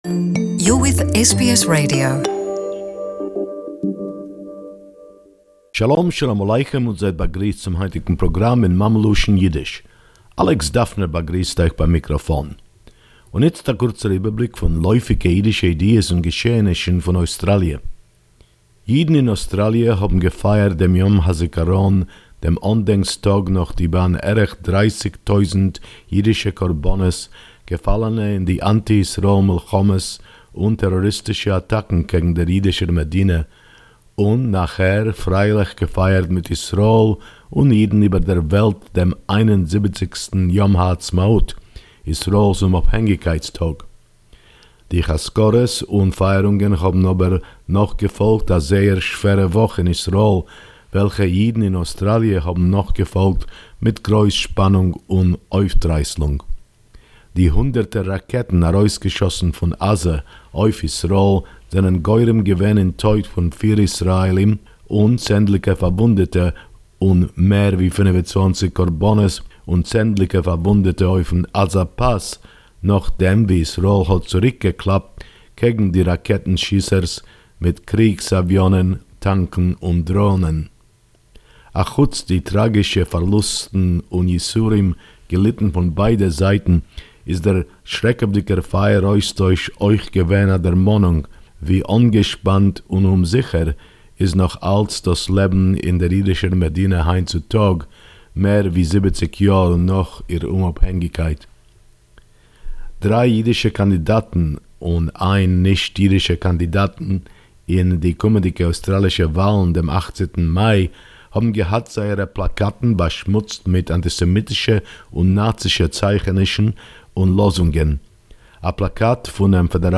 Yiddish report 12.5.2019